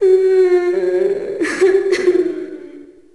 Index of /Downloadserver/sound/zp/zombie/female/